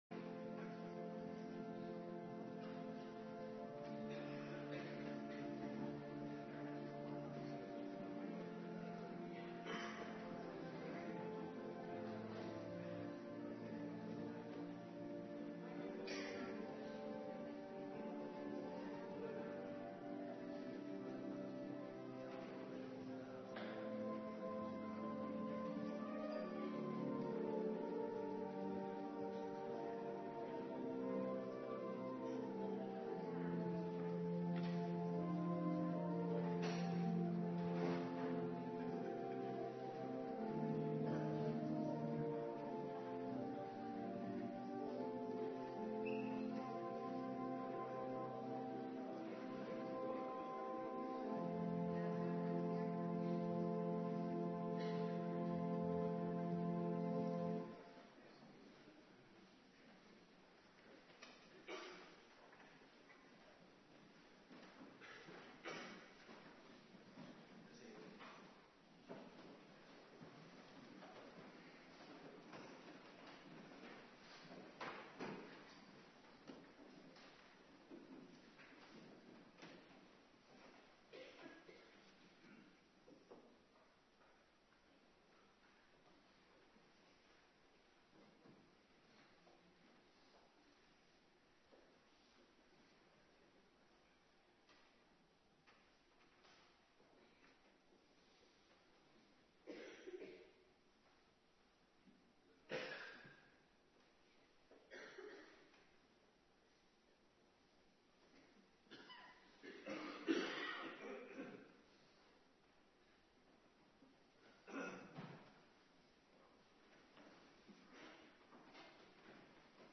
Preken (tekstversie) - Geschriften - HC zondag 52a | Hervormd Waarder